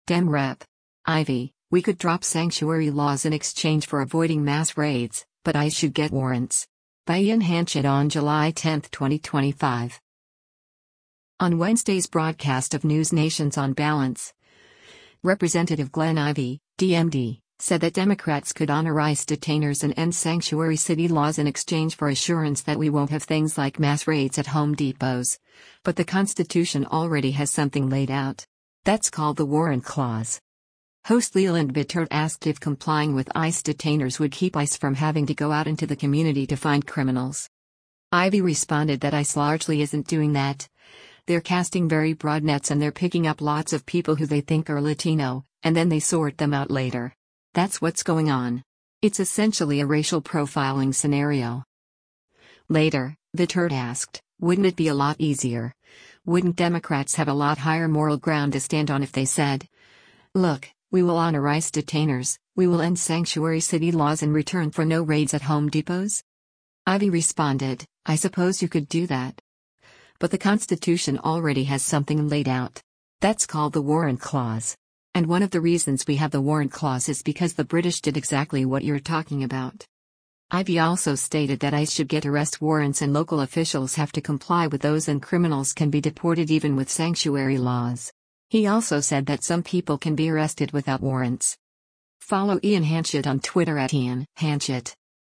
On Wednesday’s broadcast of NewsNation’s “On Balance,” Rep. Glenn Ivey (D-MD) said that Democrats could honor ICE detainers and end sanctuary city laws in exchange for assurance that we won’t have things like mass raids at Home Depots, “But the Constitution already has something laid out. That’s called the Warrant Clause.”
Host Leland Vittert asked if complying with ICE detainers would keep ICE from having to go out into the community to find criminals.